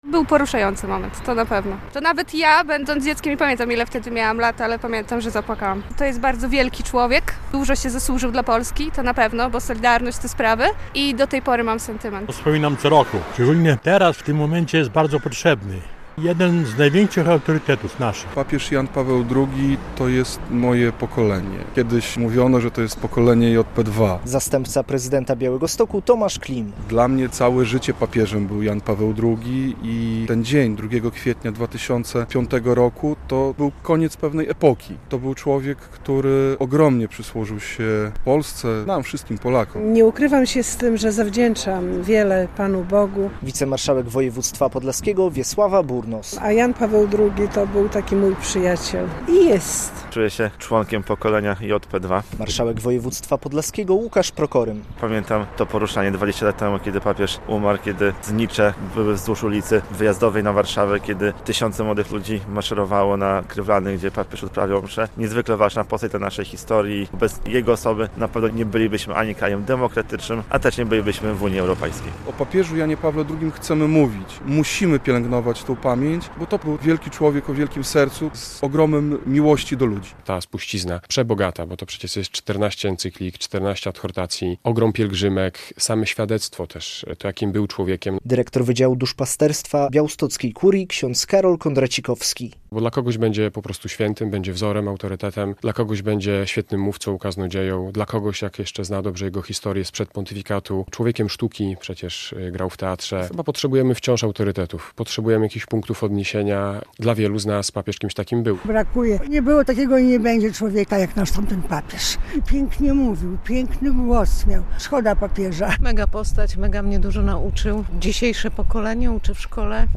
Białostoczanie i przedstawiciele władz miasta i województwa wspominają Jana Pawła II - relacja